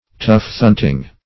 Tufthunting \Tuft"hunt`ing\, n.